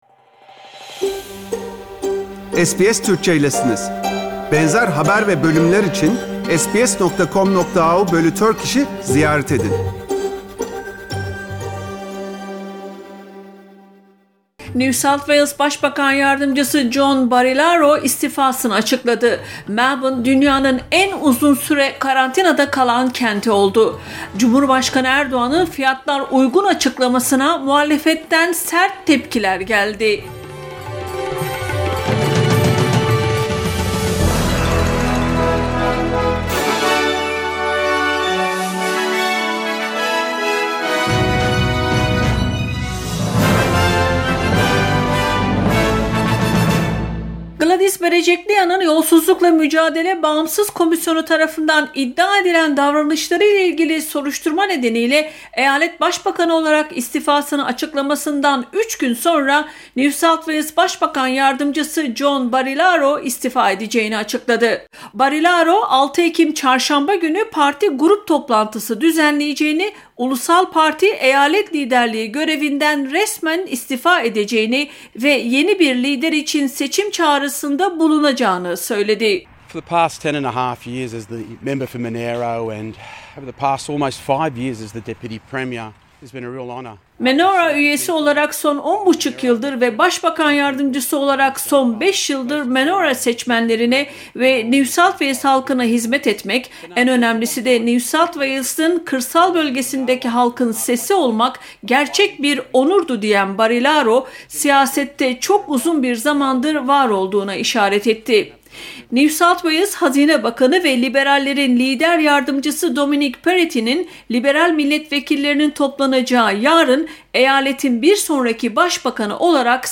SBS Türkçe Haberler 4 Ekim